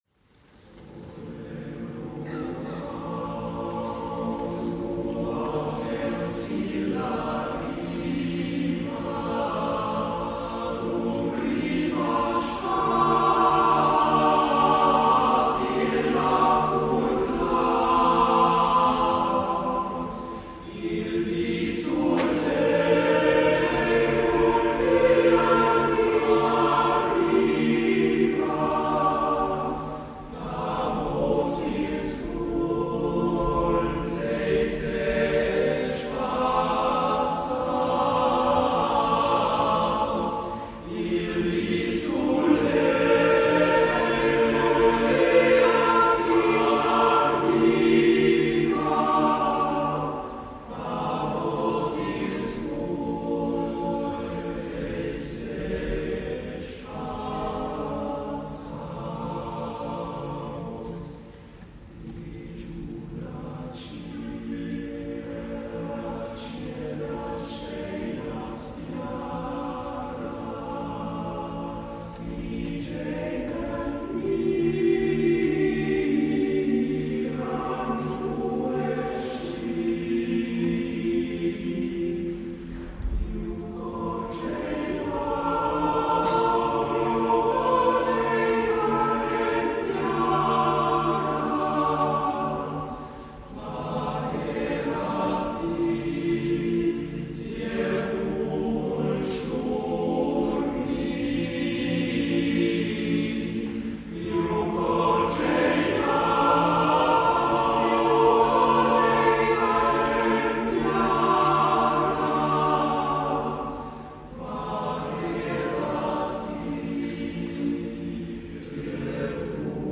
Chœur d’hommes fondé en 1860
Chant grison
Interprété par le Chœur du Léman en : 2025
H10544-Live.mp3